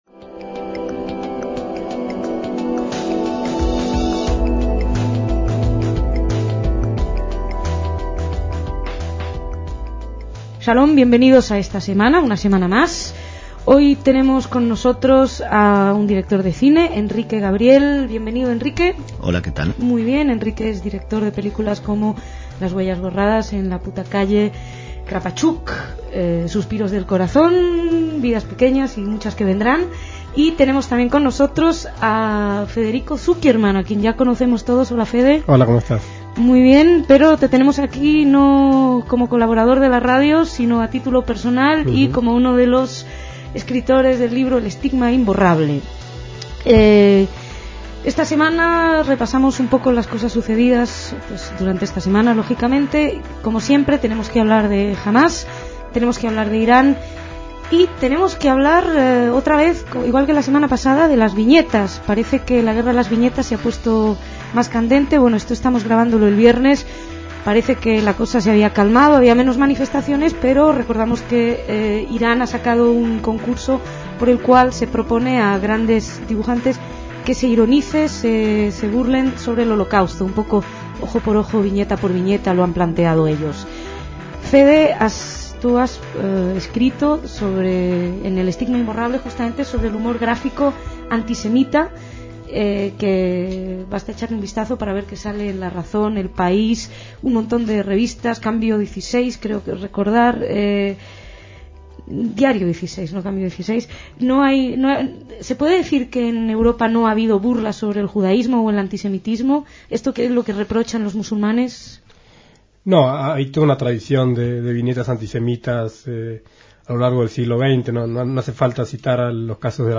El debate semanal